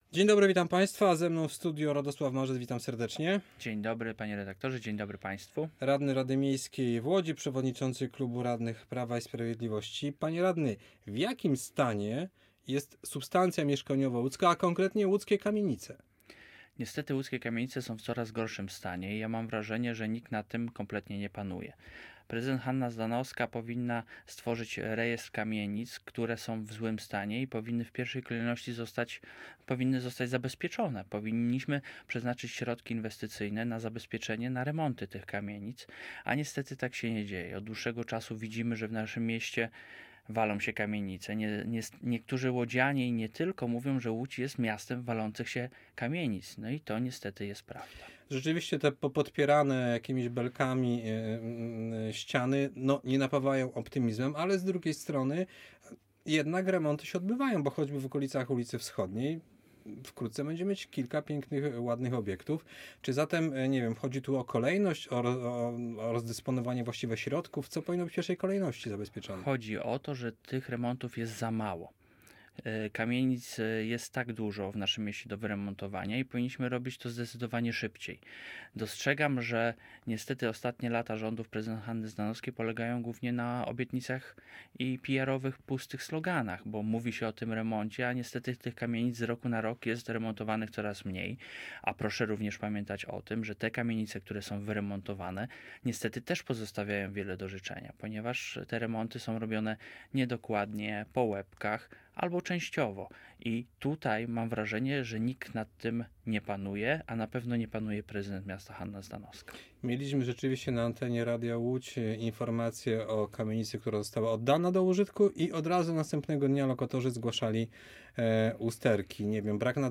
Gościem po 8. w środę (10 sierpnia) był Radosław Marzec – przewodniczący klubu radnych PiS w Radzie Miejskiej w Łodzi. Rozmawialiśmy o stanie łódzkich kamienic i o nastrojach wśród pracowników miejskich spółek.
Posłuchaj rozmowy: Nazwa Plik Autor Radosław Marzec audio (m4a) audio (oga) Zdaniem naszego porannego gościa, w Łodzi jest za mało remontów kamienic choć są na to środki unijne.